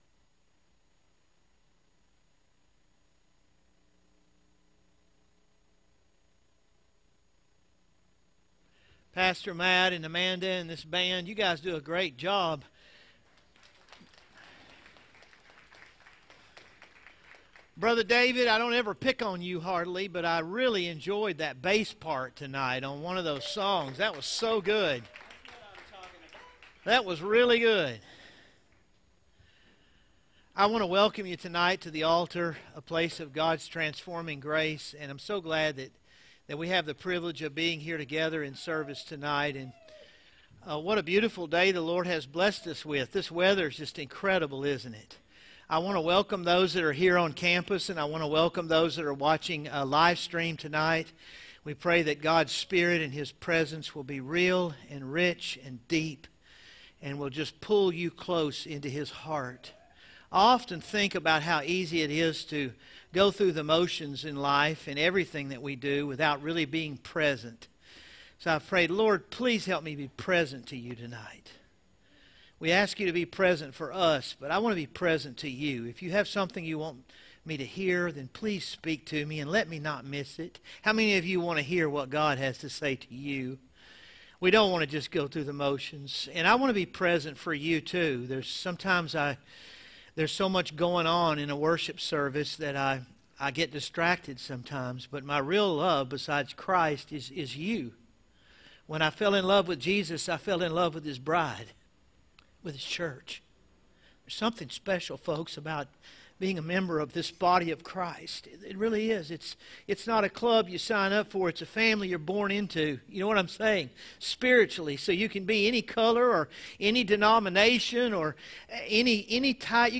From Series: "Standalone Sermons "